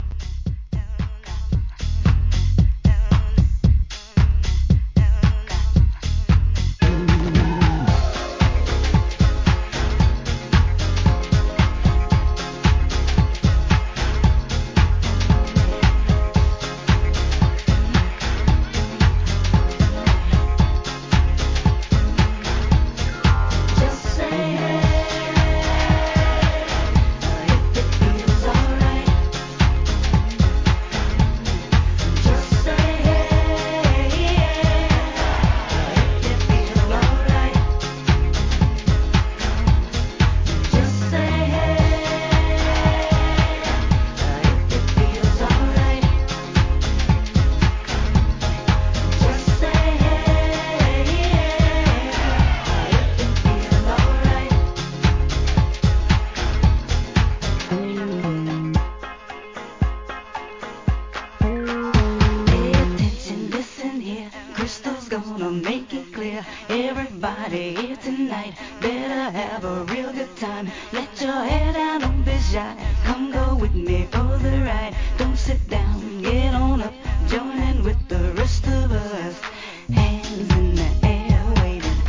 HIP HOP/R&B
使いの盛り上がり必至アップ・ナンバー!
CLUB MIX